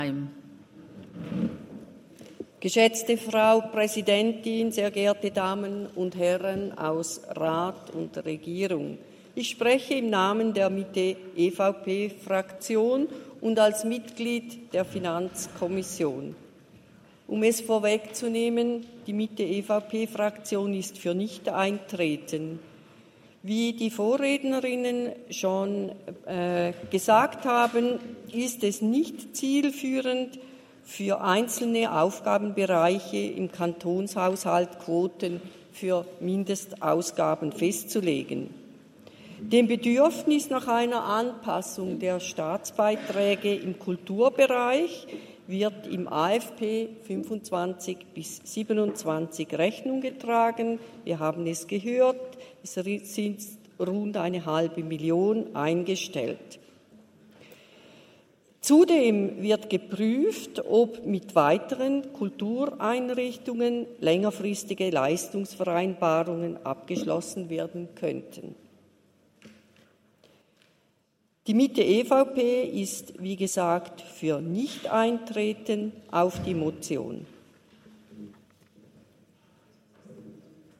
Session des Kantonsrates vom 29. April bis 2. Mai 2024, Aufräumsession
1.5.2024Wortmeldung
Scherrer-Degersheim (im Namen der Mitte-EVP-Fraktion und als Mitglied der Finanzkommission): Auf die Motion ist nicht einzutreten.